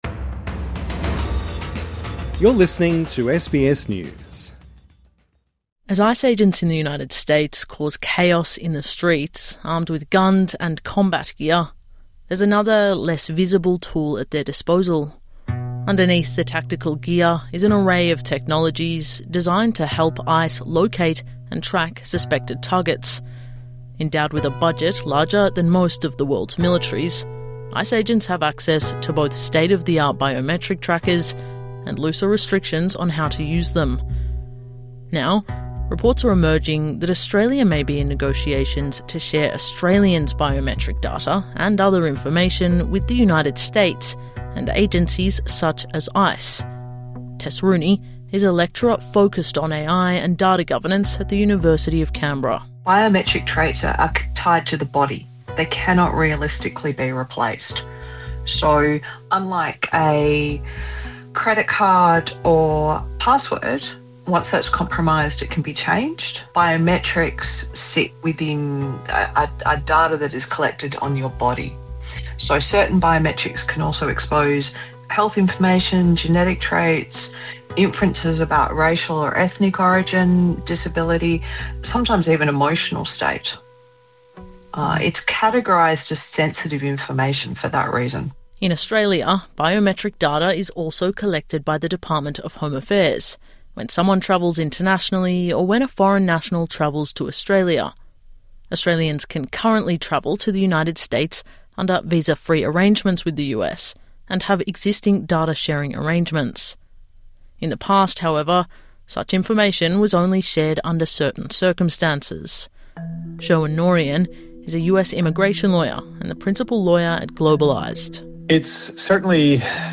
In each episode, well help you make sense of the news stories that matter to you from Australia and the world, with reports and interviews from the SBS News team.